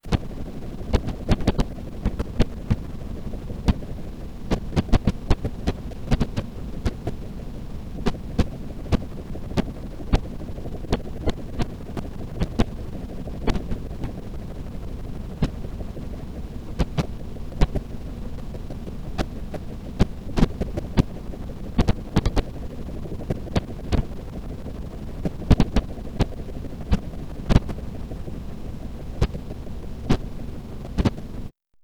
нормализация обоих файлов - инвертирование вторго - смикшировать в новую дорожку. нормализованный результат приложил